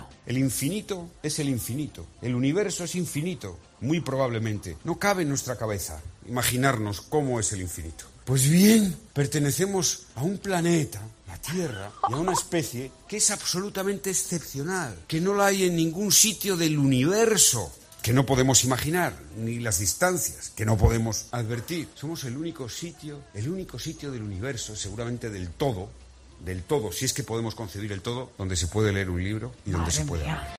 Es lo que le ha pasado al expresidente del Gobierno José Luis Rodríguez Zapatero, que, en un mitín en Pamplona, ha dejado ojipláticos a los asistentes divagando sobre el infinito y el universo.